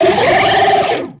Grito de Quilladin.ogg
Grito_de_Quilladin.ogg.mp3